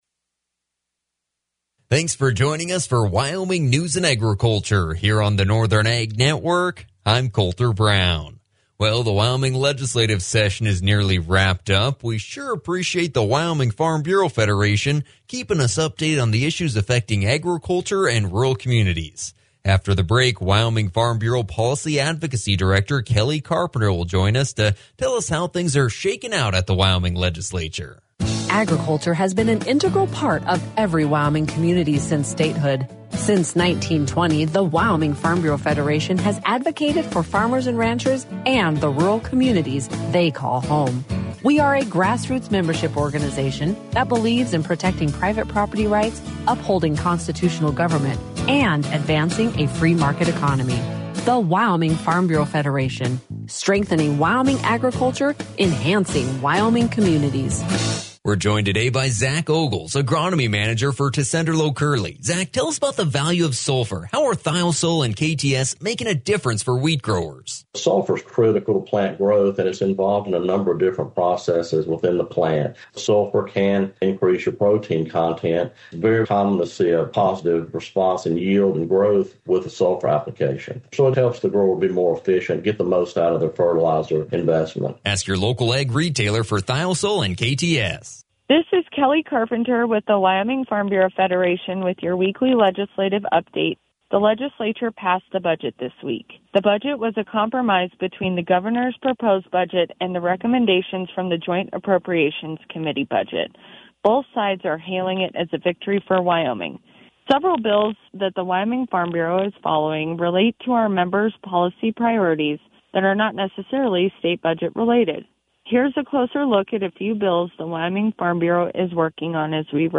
WyFB will be live on Northern Ag Network Radio every Thursday at 3:10!